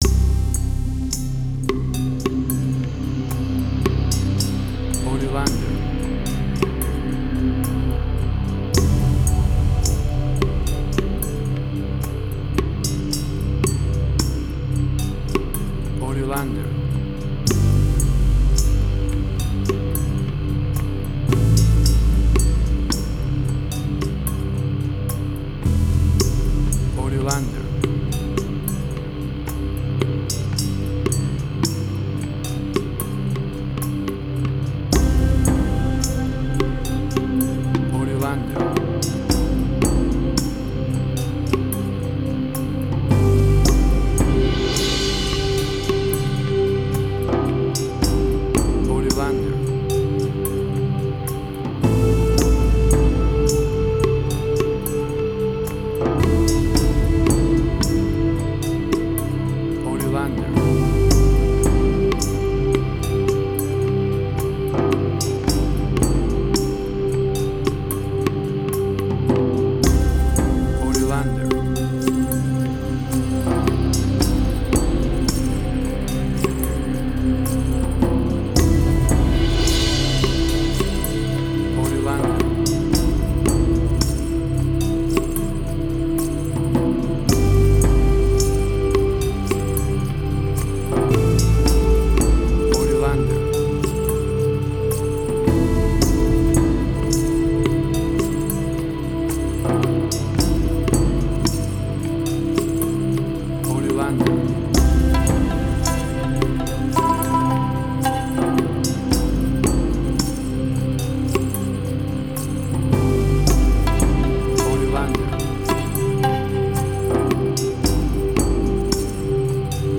New Age.
Tempo (BPM): 55